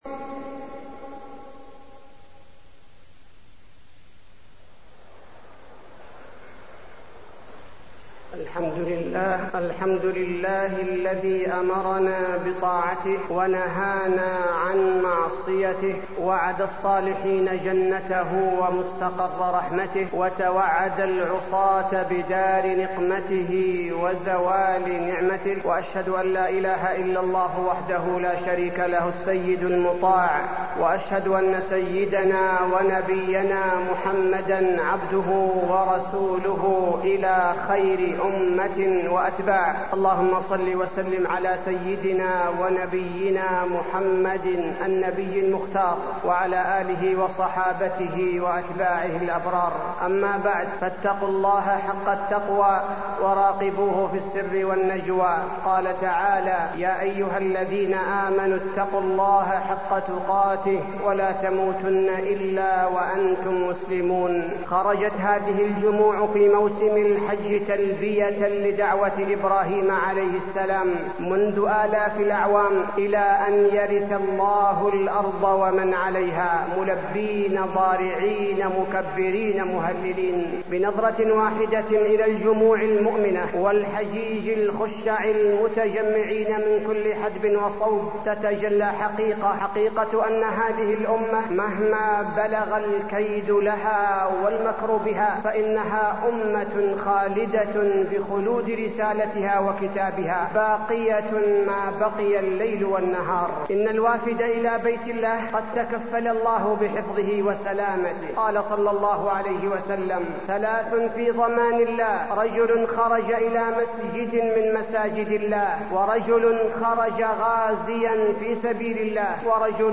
تاريخ النشر ٢٨ ذو القعدة ١٤٢٣ هـ المكان: المسجد النبوي الشيخ: فضيلة الشيخ عبدالباري الثبيتي فضيلة الشيخ عبدالباري الثبيتي الحج